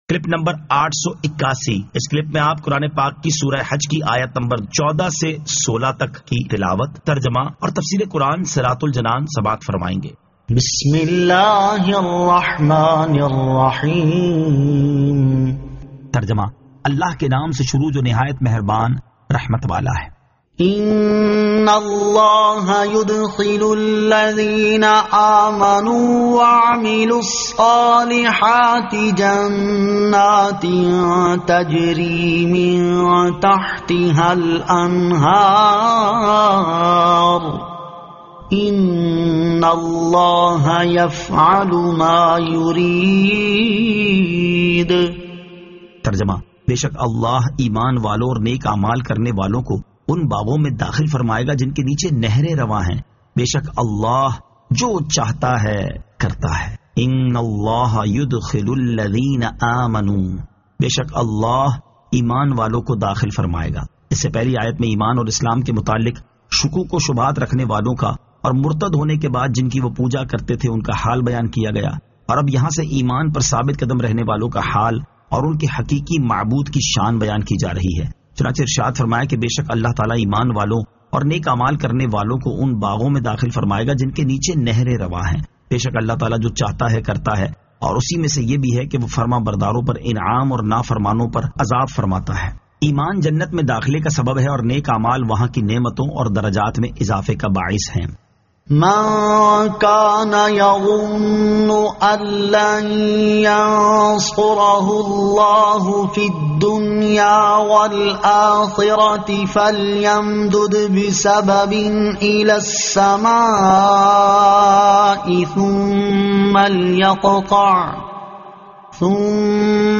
Surah Al-Hajj 14 To 16 Tilawat , Tarjama , Tafseer
2022 MP3 MP4 MP4 Share سُورَۃُ الْحَجِّ آیت 14 تا 16 تلاوت ، ترجمہ ، تفسیر ۔